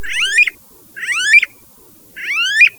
Smooth-billed Ani
Crotophaga ani
VOZ: Los llamados incluyen un graznido ascendente "ki-φφk, ki-φφk" y un "ker-r-rk" mßs bajo.